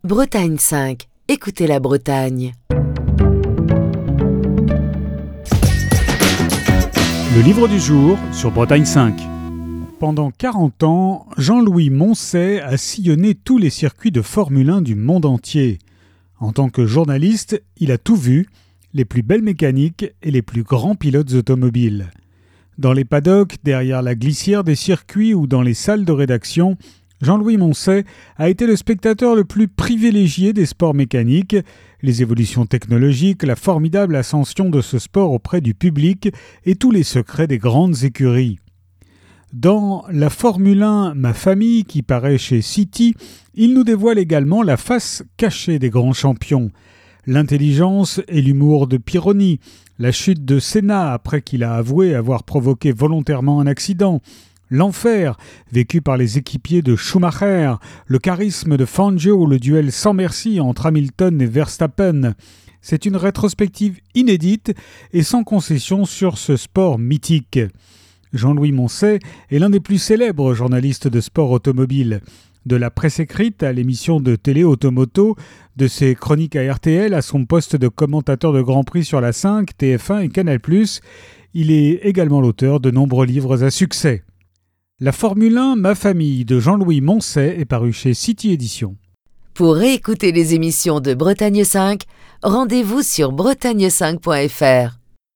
Chronique du 10 octobre 2022.